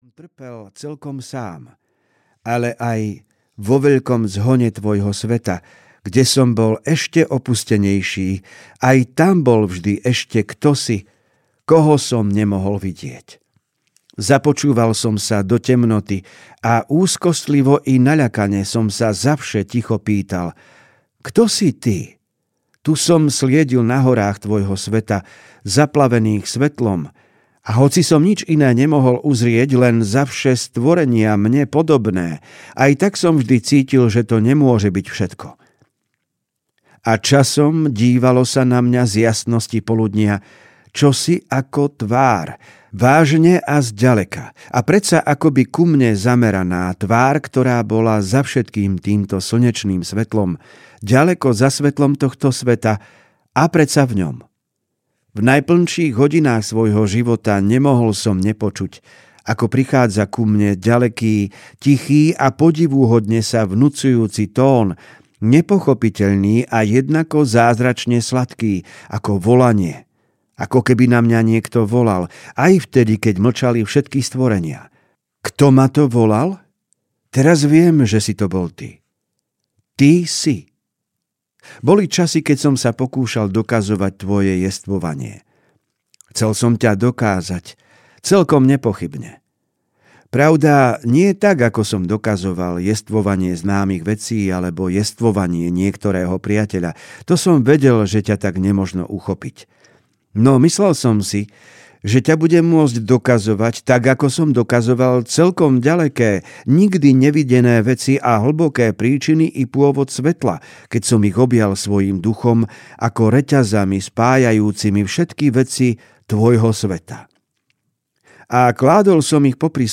Človek Jób hovorí s Bohom audiokniha
Ukázka z knihy